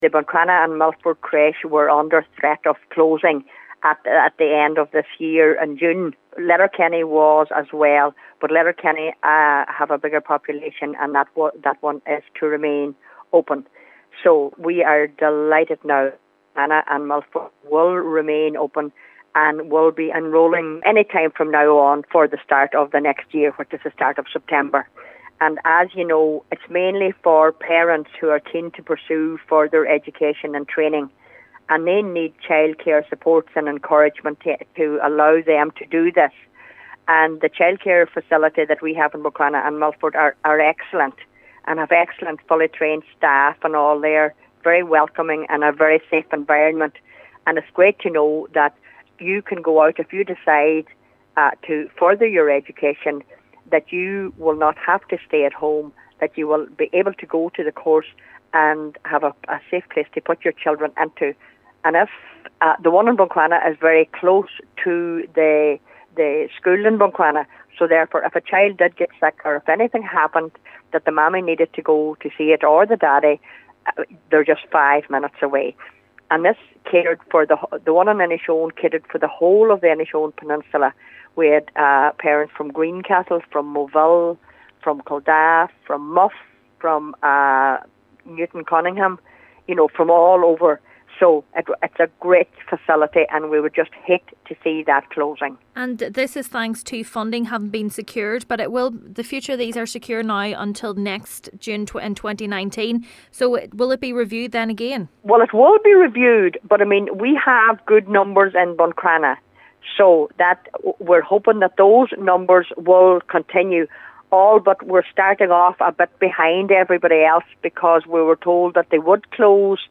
Councillor Rena Donaghey says the enrollment process is already underway for next year: